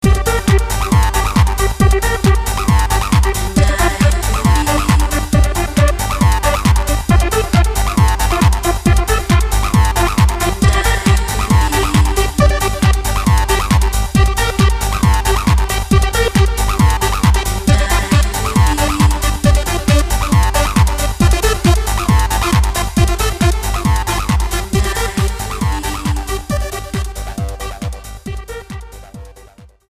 STYLE: Dance/Electronic